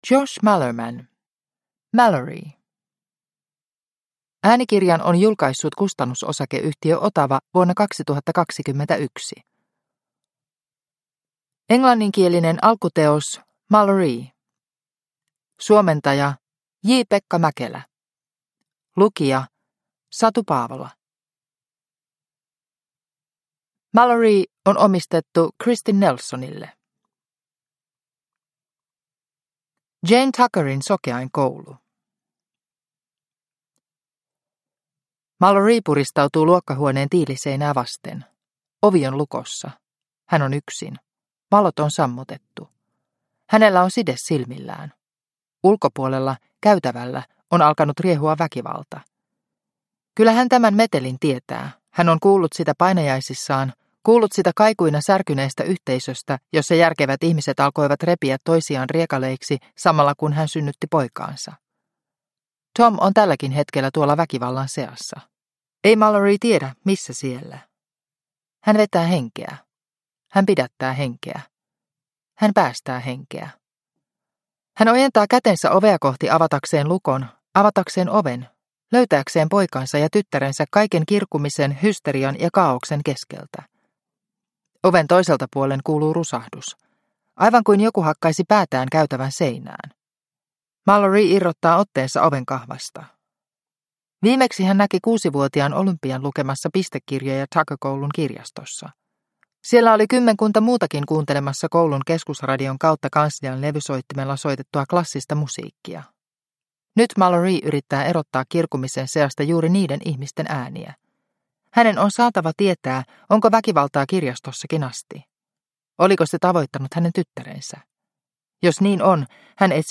Malorie - Älä katso, älä kosketa – Ljudbok – Laddas ner